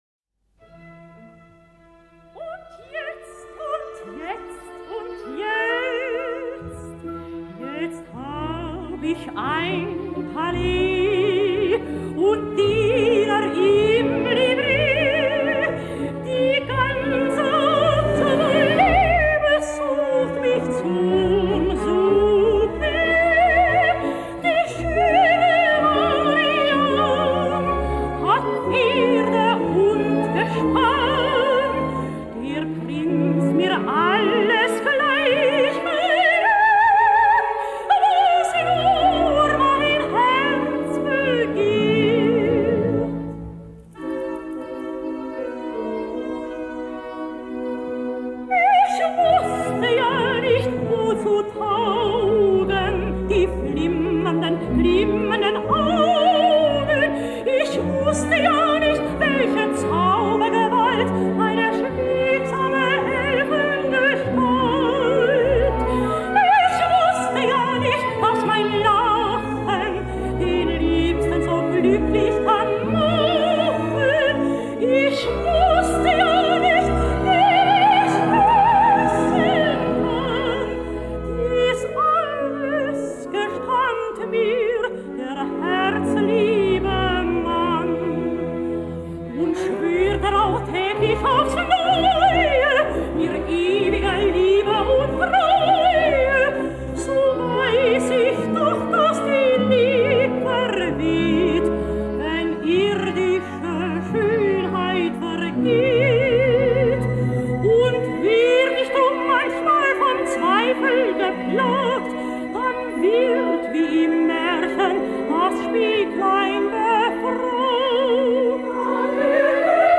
Operette in drei Akten
Mariann, seine Tochter aus zweiter Ehe Sopran